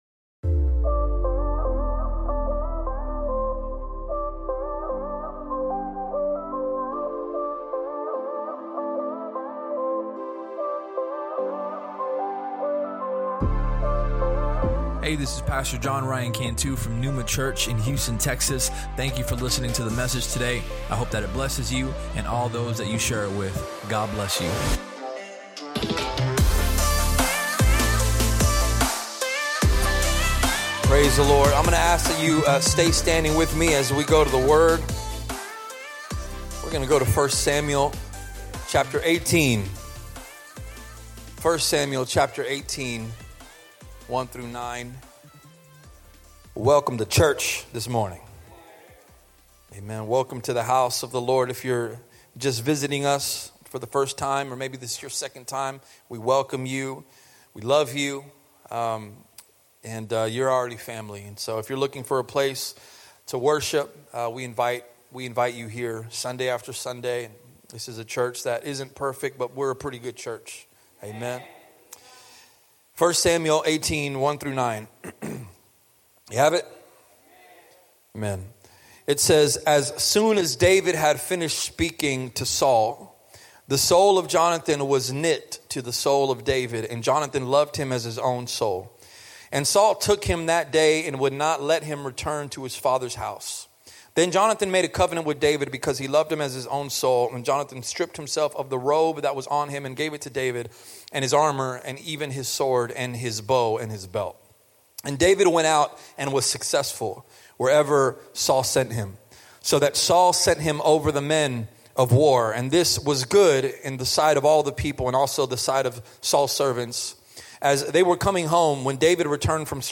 Sermon Topics: Influence, Humility, Maturity If you enjoyed the podcast, please subscribe and share it with your friends on social media.